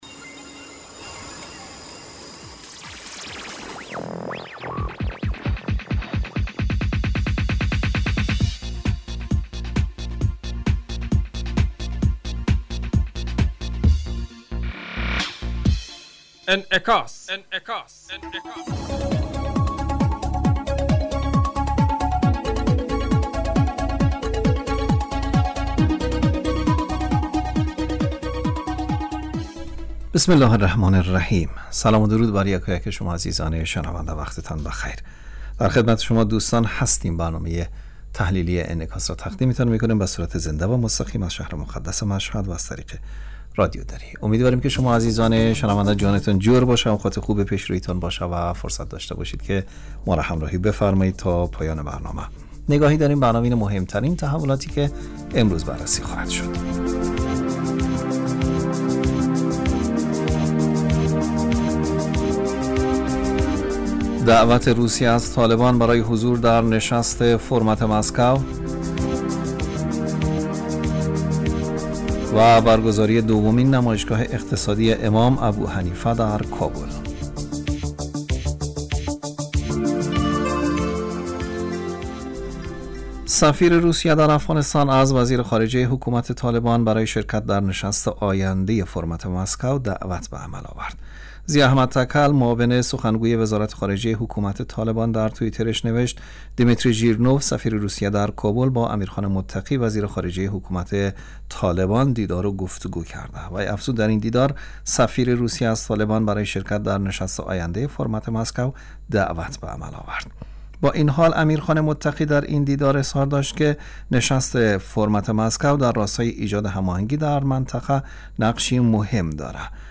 برنامه انعکاس به مدت 35 دقیقه هر روز در ساعت 06:50 بعد از ظهر (به وقت افغانستان) بصورت زنده پخش می شود.